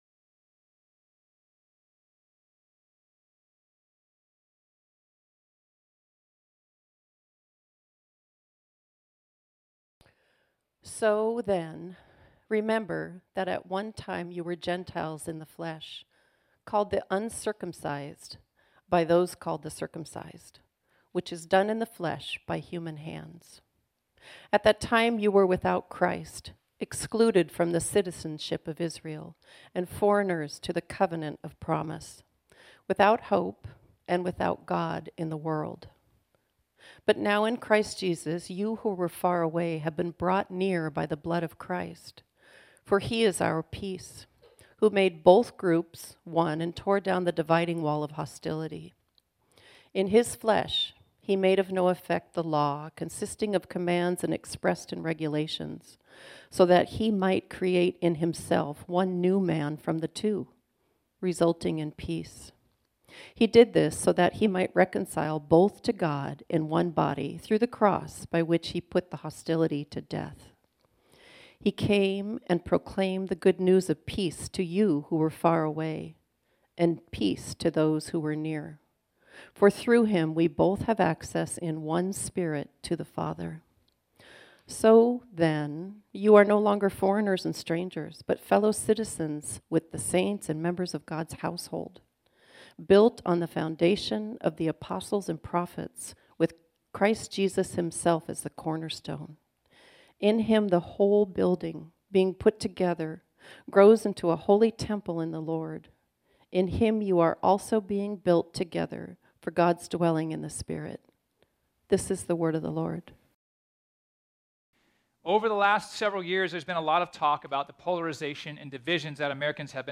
This sermon was originally preached on Sunday, October 1, 2023.